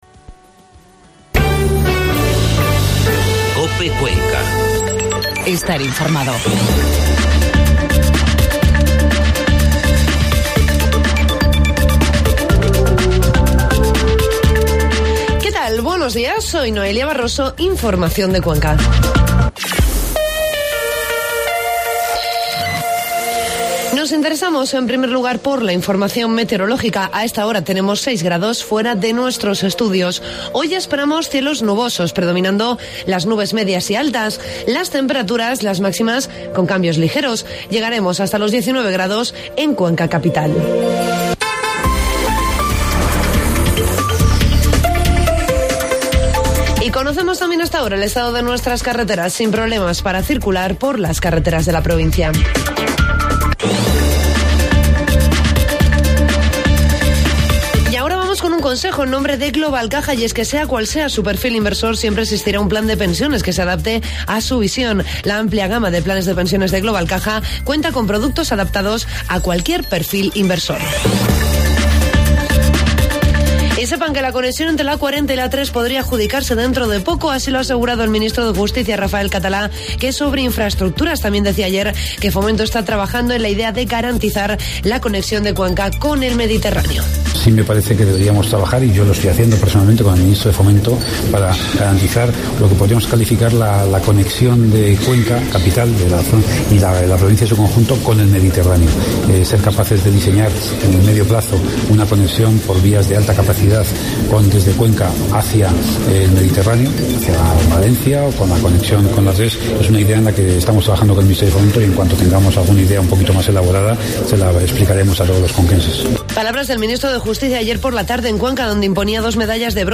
AUDIO: Informativo matinal